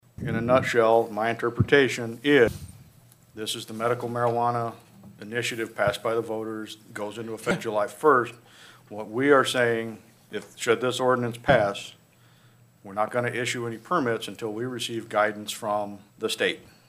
The Mobridge City Council held a special meeting Tuesday, June 1, 2021, with the Planning Commission for the First Reading of Ordinance #996 – A Temporary Ordinance Regarding the Issuance of Local Cannabis Establishment Permits and/or Licenses.  Mayor Gene Cox explained the meaning of the ordinance.